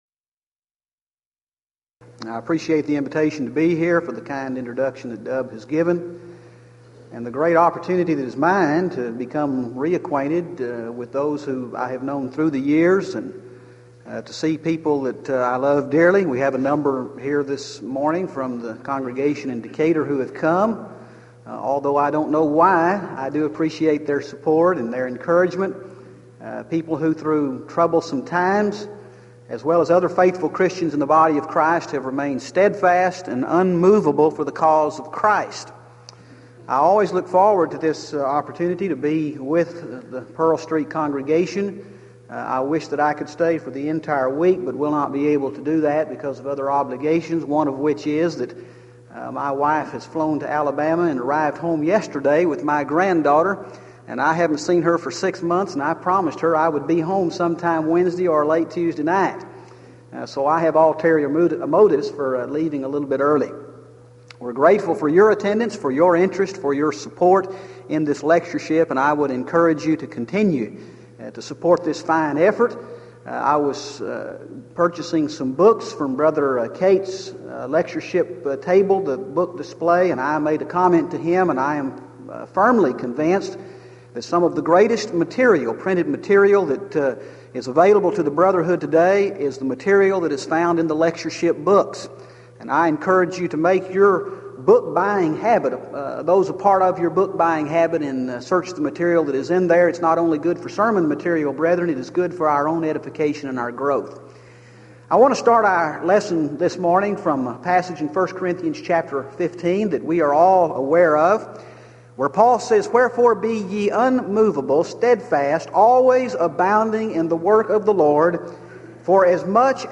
Series: Denton Lectures Event: 1993 Denton Lectures